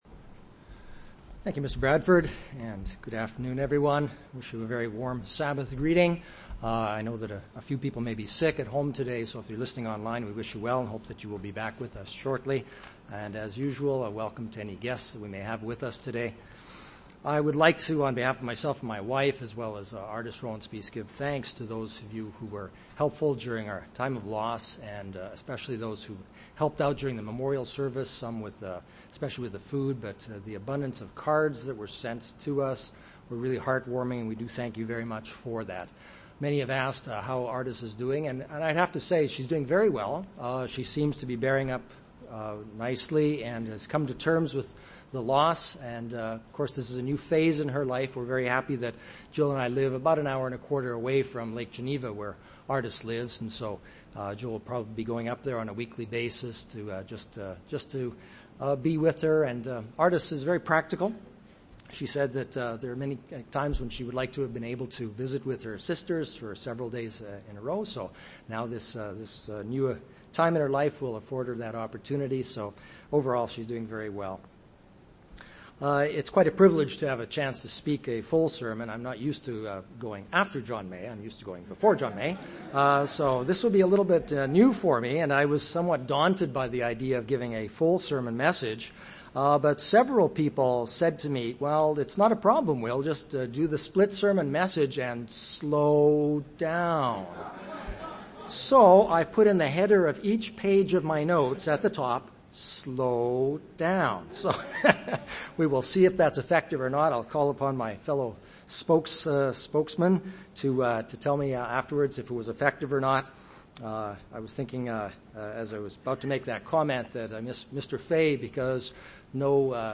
UCG Sermon Studying the bible?
Given in Chicago, IL